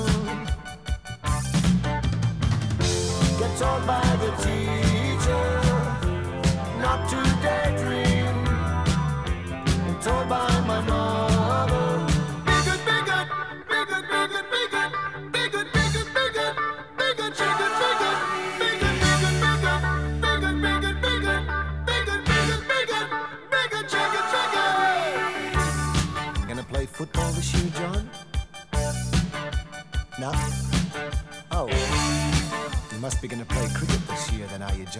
# Поп